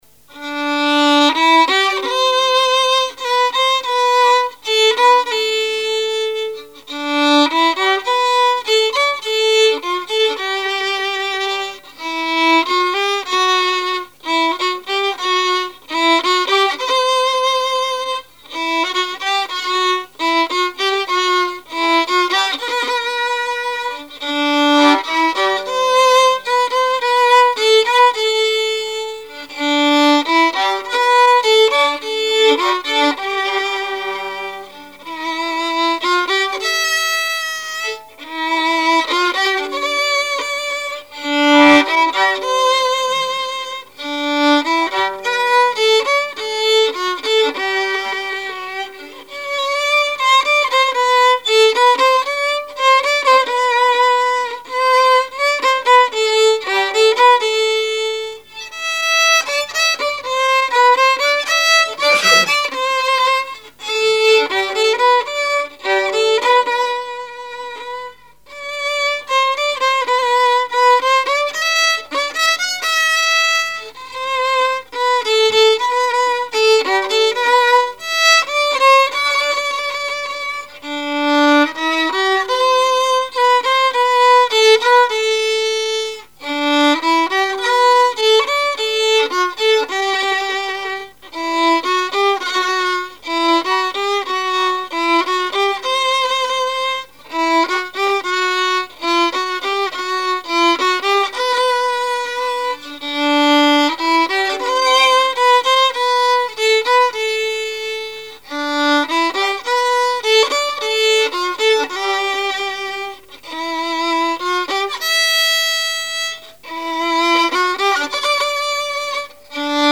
musique varieté, musichall
Saint-Christophe-du-Ligneron
Répertoire musical au violon
Pièce musicale inédite